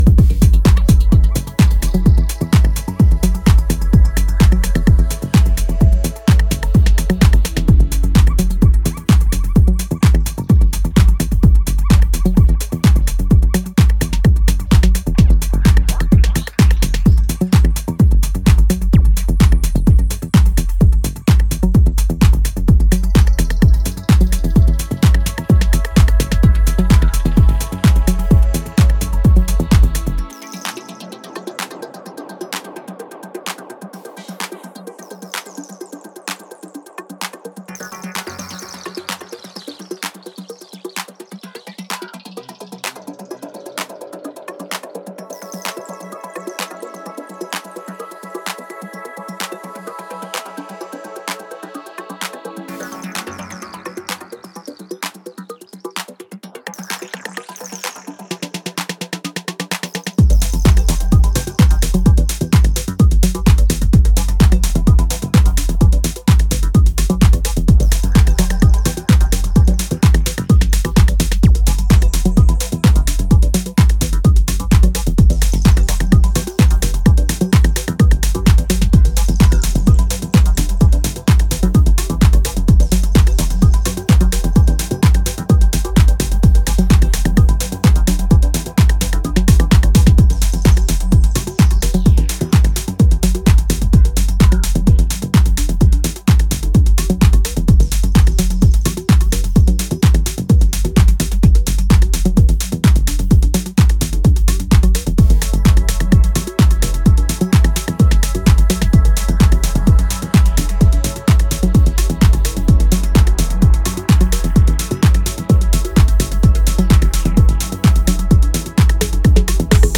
highly energetic, spacey and vibrant sound